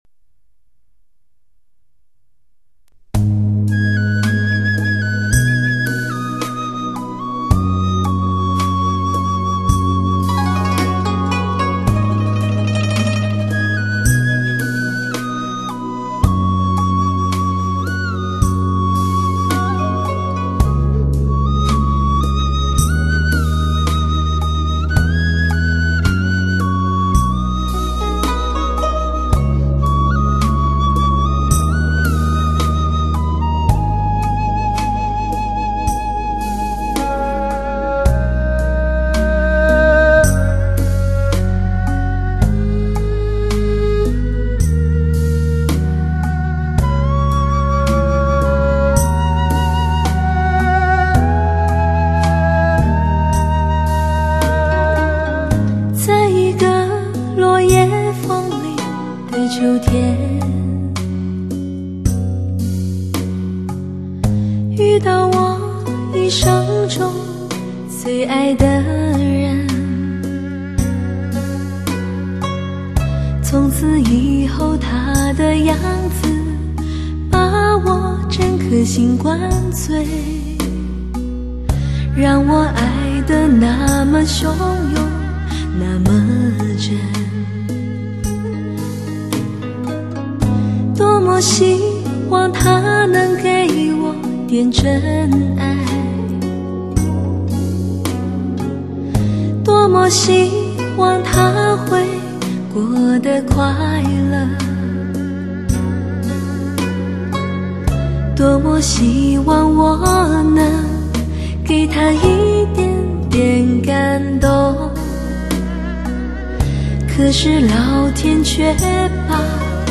Music Stereo To Surround 7.1 Hi-Fi 3D多音色高临